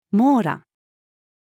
網羅-female.mp3